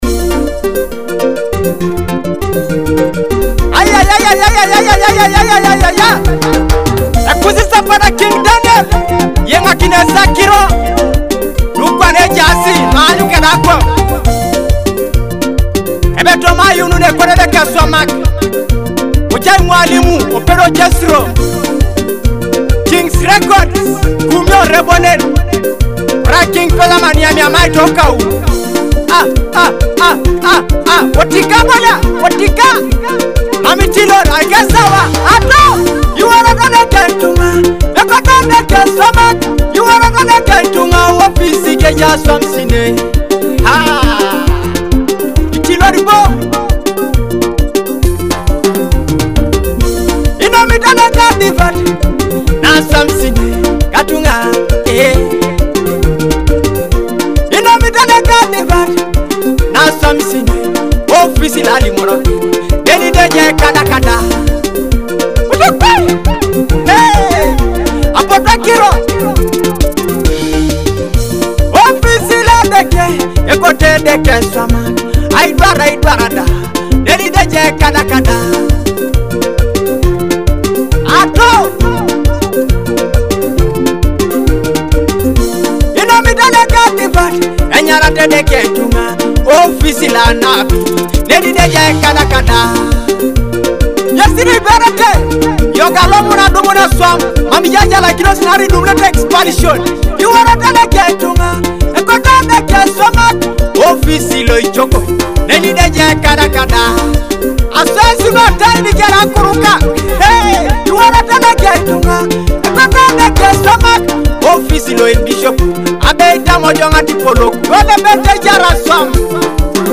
Ateso gospel song